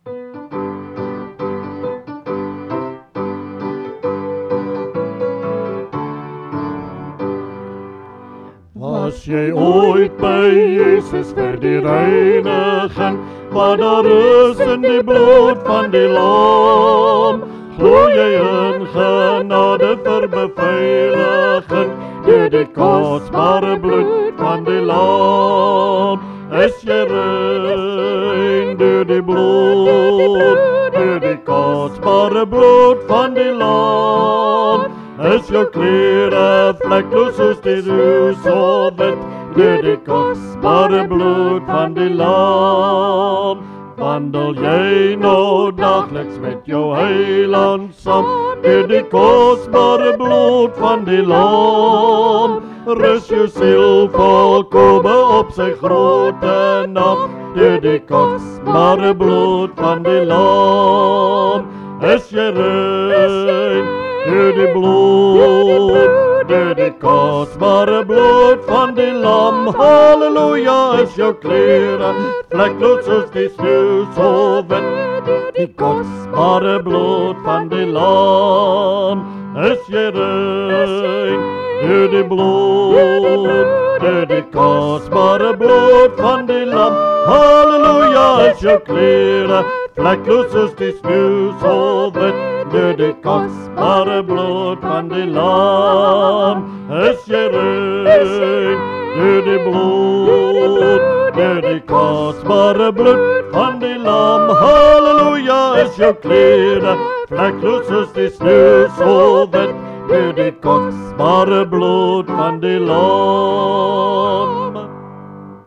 Liedere - Begeleiding en samesang
Dit is geensins professionele opnames nie.